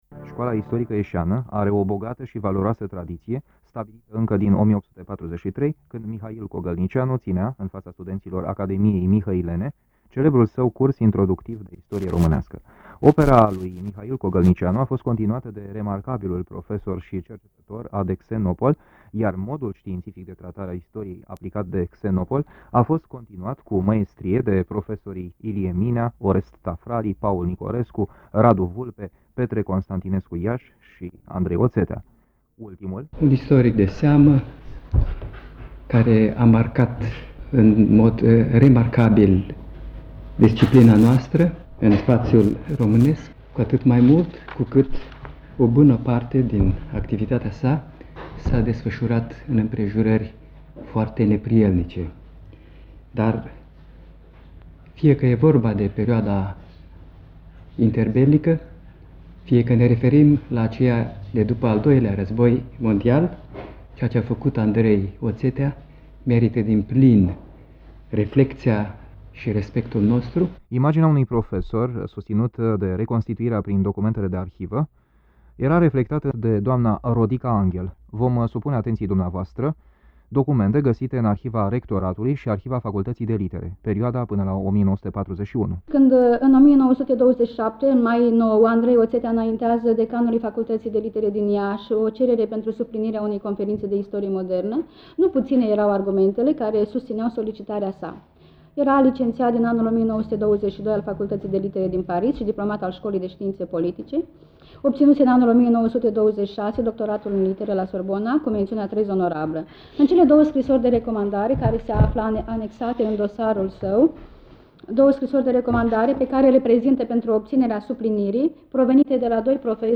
înregistrare-document / Simpozion organizat, pe 2 noiembrie 1994, de Institutul „A. D. Xenopol”-Iași